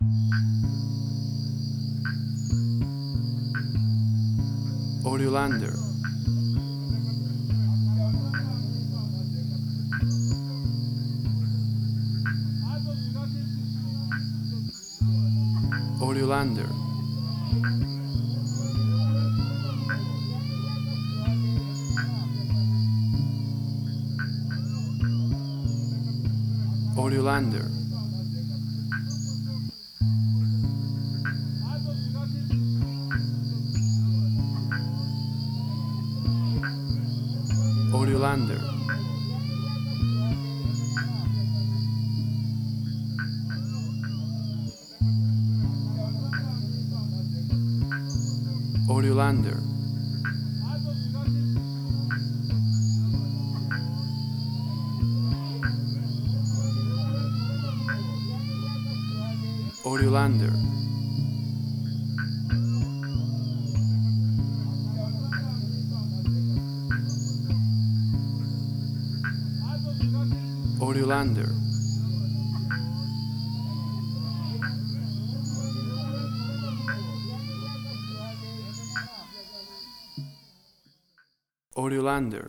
Suspense, Drama, Quirky, Emotional.
WAV Sample Rate: 16-Bit stereo, 44.1 kHz
Tempo (BPM): 96